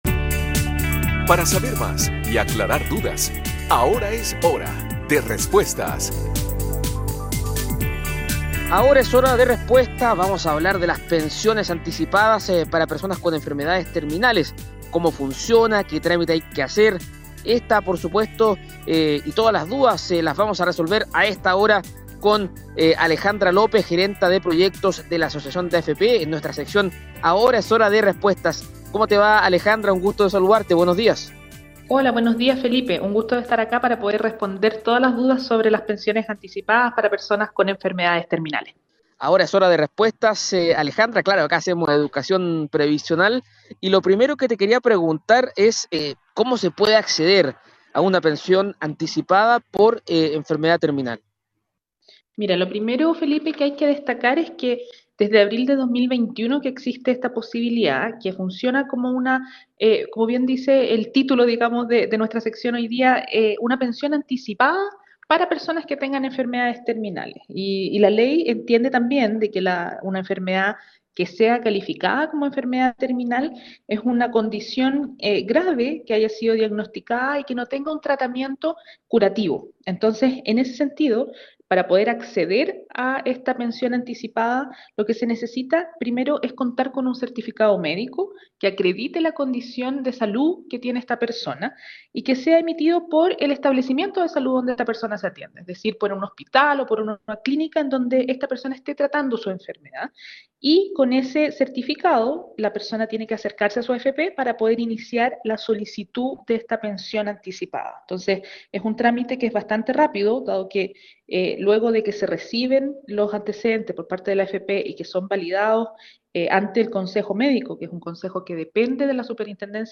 En el programa de Radio Cooperativa Ahora es Hora